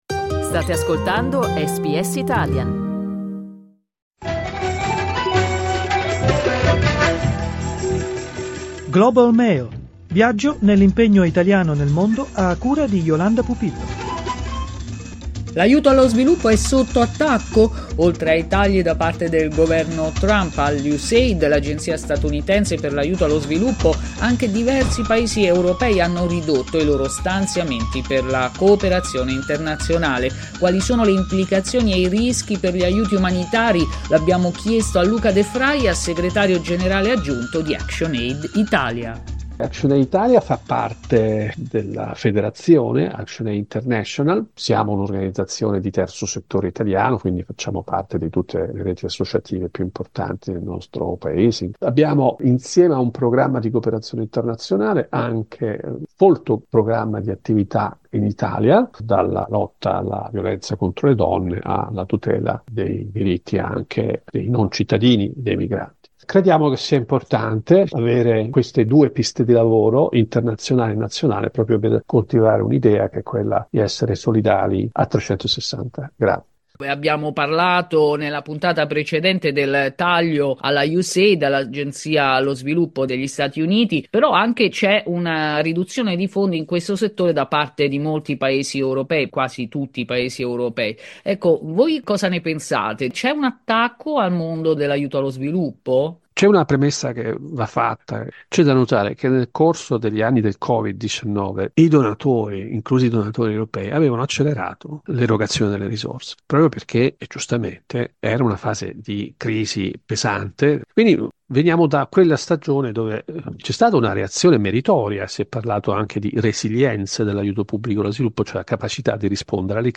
SBS in Italiano View Podcast Series